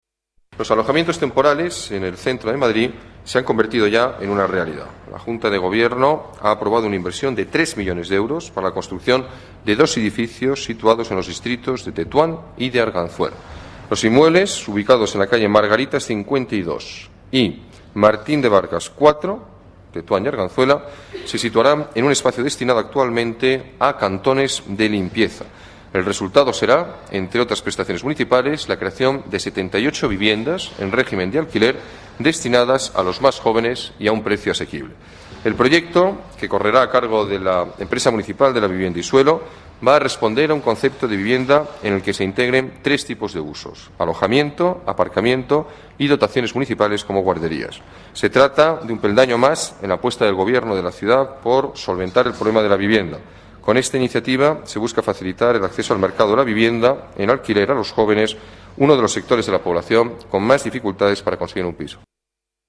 Nueva ventana:Alberto Ruiz Gallardón se refiere al proyecto de alojamientos de alquiler para jóvenes aprobado hoy por la Junta de Gobierno de la Ciudad de Madrid